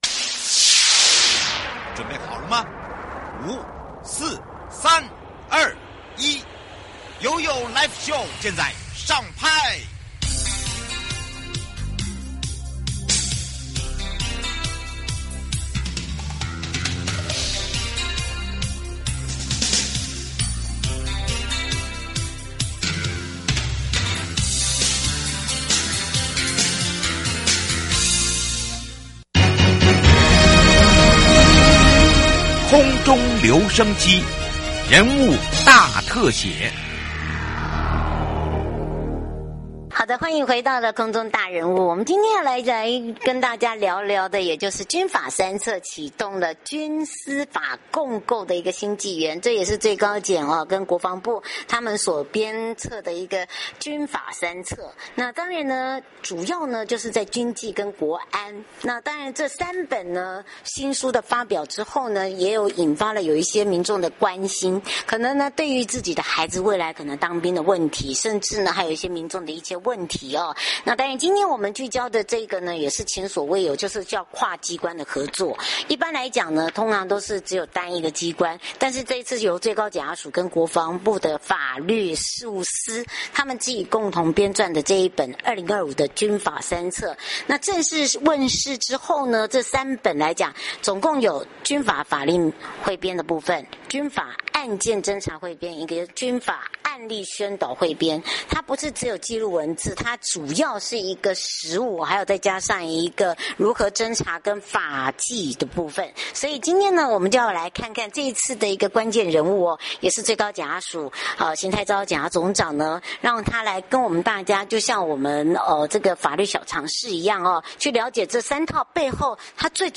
最高檢察署邢泰釗檢察總長，來與我們深入對談。一起了解這套三書背後的理念、目的與對軍紀、司法、國安的重要意義。